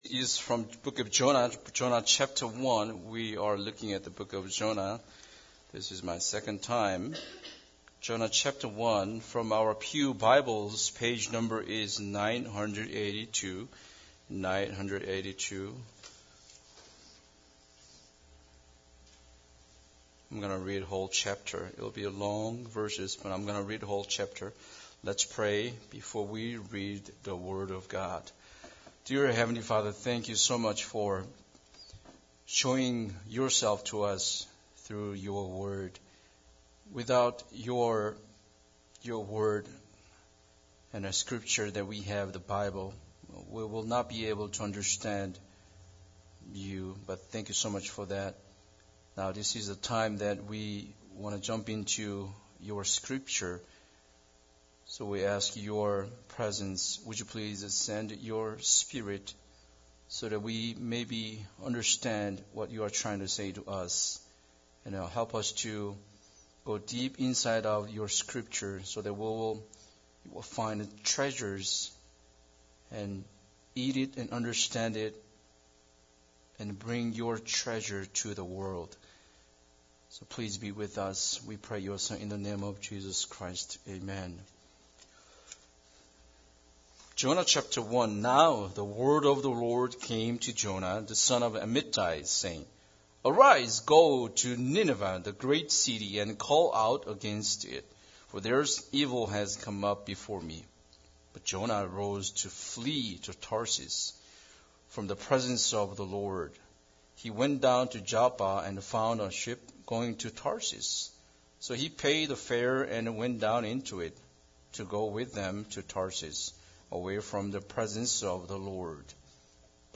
John 1 Service Type: Sunday Service Bible Text